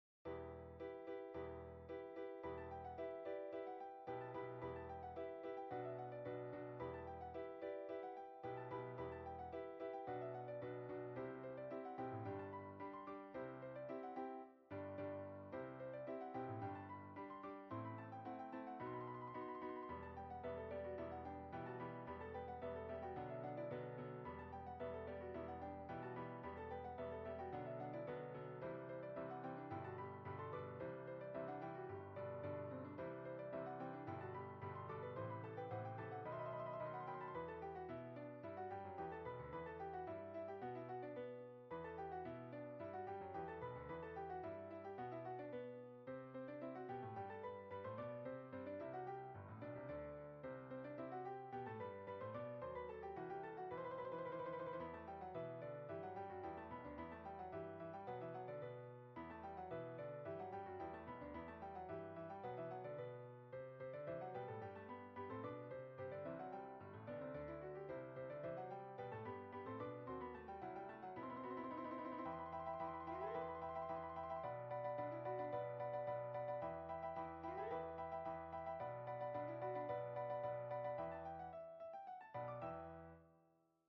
générique - partition pour 2 pianos